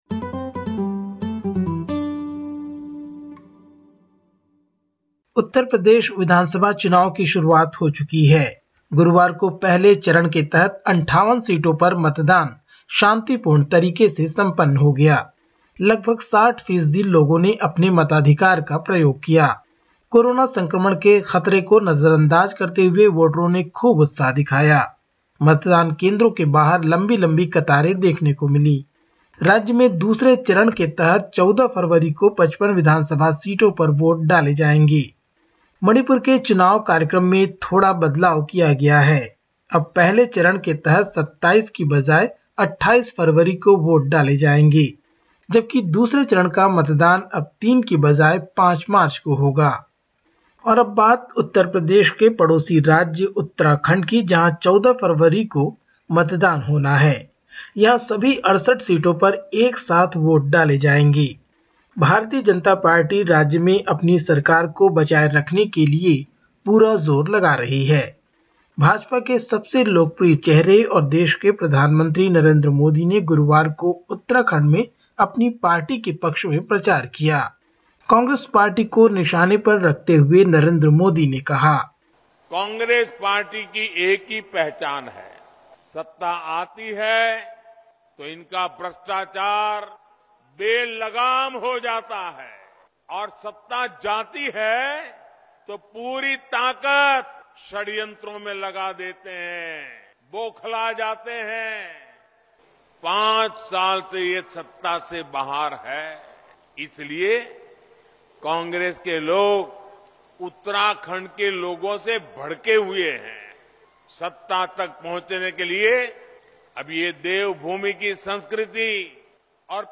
Listen to the latest SBS Hindi report from India. 11/02/2022